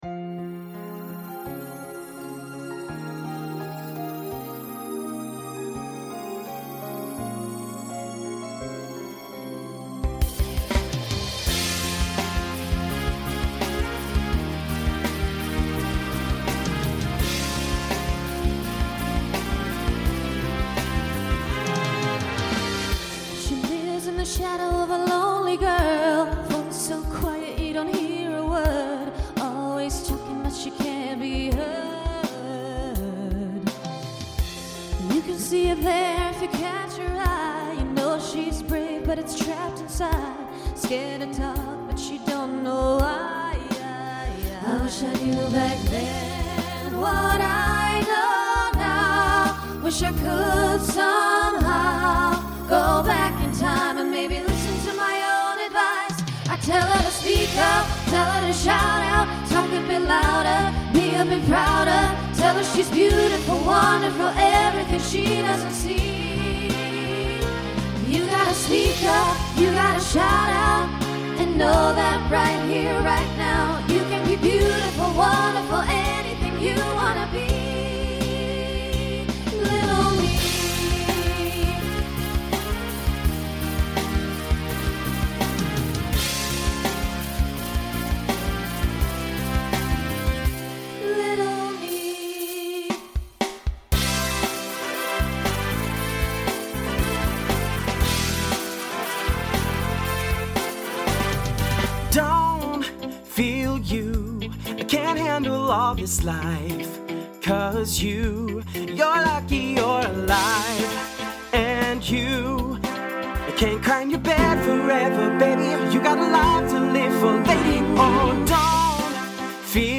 SSA/TTB/SATB
Instrumental combo Genre Pop/Dance